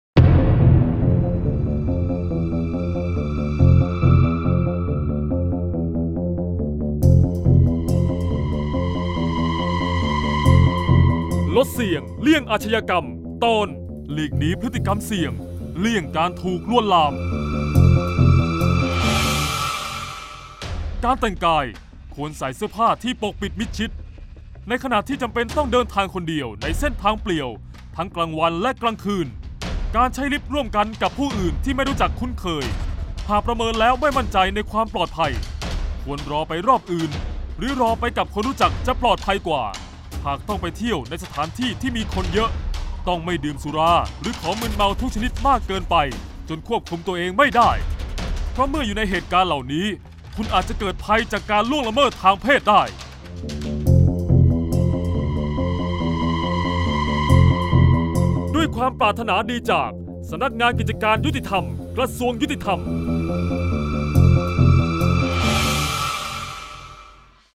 เสียงบรรยาย ลดเสี่ยงเลี่ยงอาชญากรรม 12-เลี่ยงถูกลวนลาม